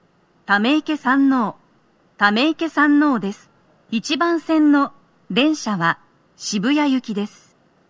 スピーカー種類 TOA天井型()
1番線 渋谷方面 到着放送 【女声